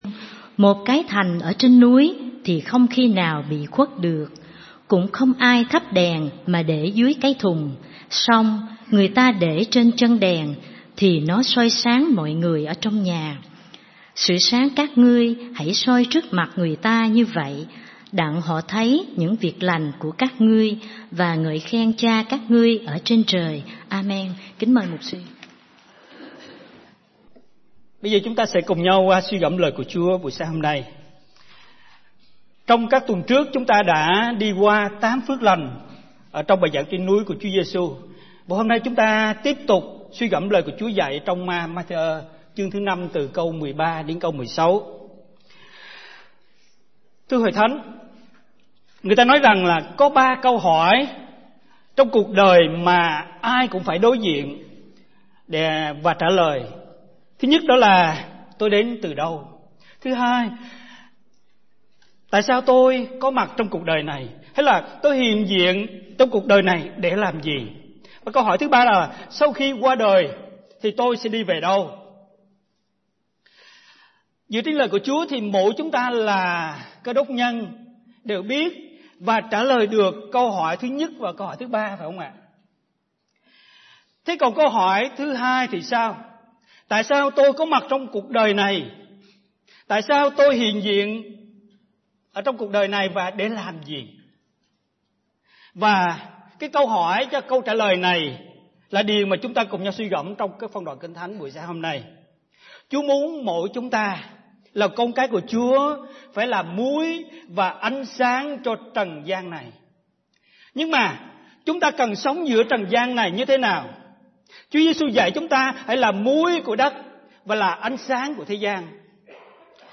Bài Giảng